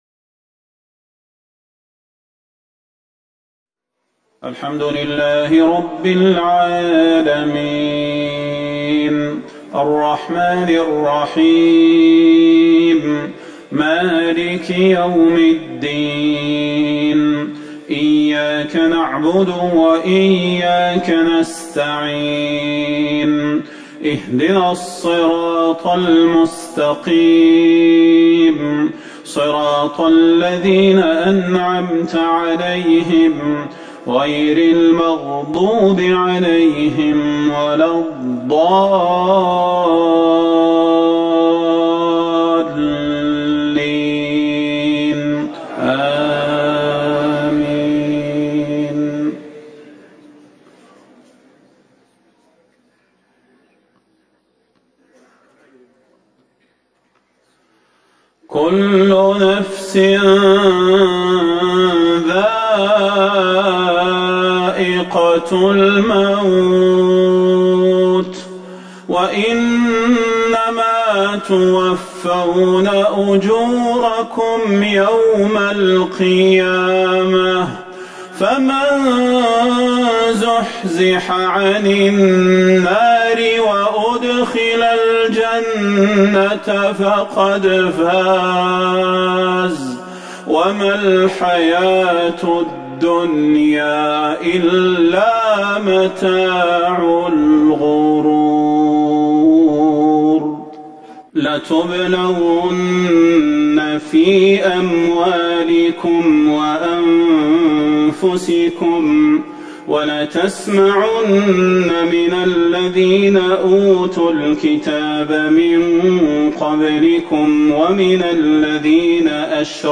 صلاة المغرب 15 جمادى الاولى 1441 من سورة آل عمران | Maghrib Prayer 3-1-2020 from Surat Al-Imran > 1441 🕌 > الفروض - تلاوات الحرمين